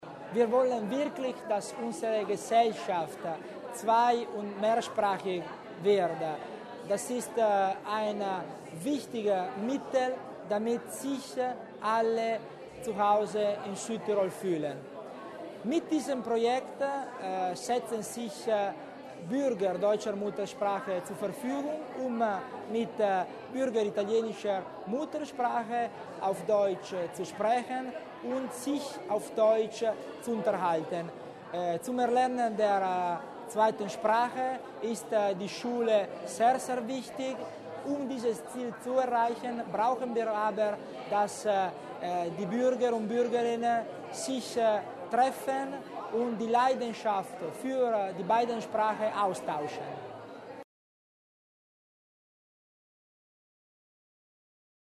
Landeshauptmannstellvertreter Tommasini zur Bedeutung des Projekts